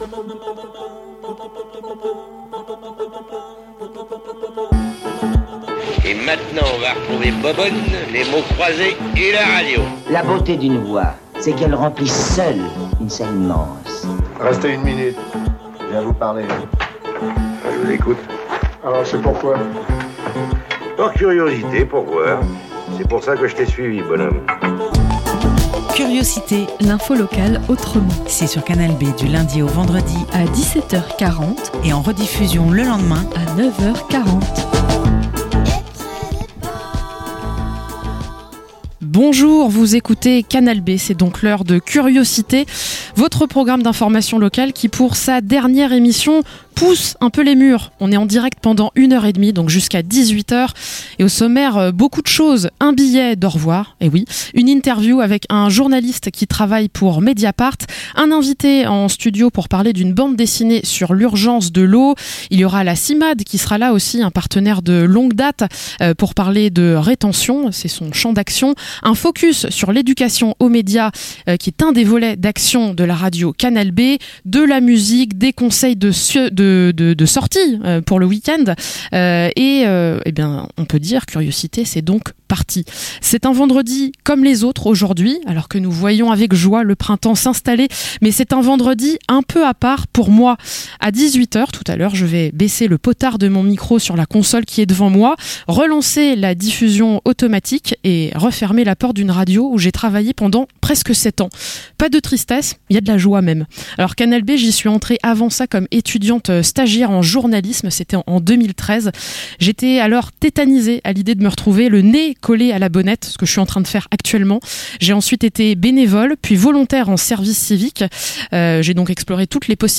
La dernière de Curiocité en direct
- Reportage - La Ferme du Turfu, un projet d'agriculture urbaine coopératif